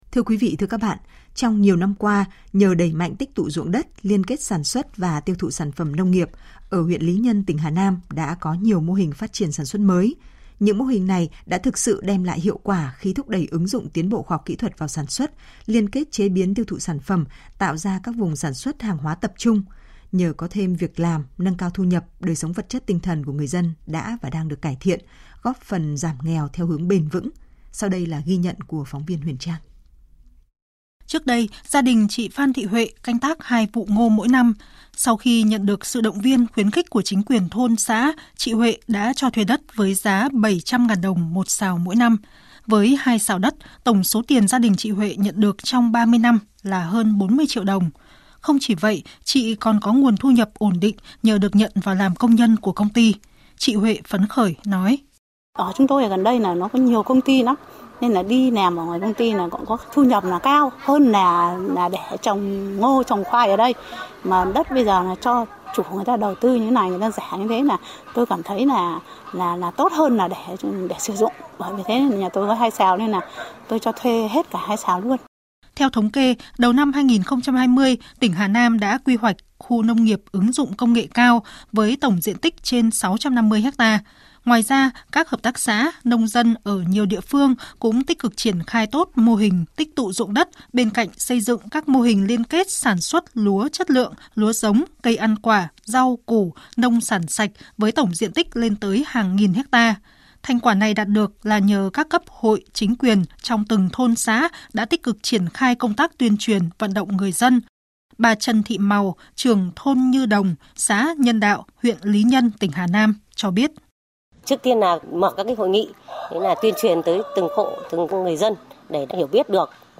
File phát thanh